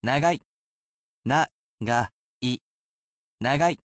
We have here with us the portable version of our computer robot friend, QUIZBO™ Mini, who will be here to help read out the audio portions.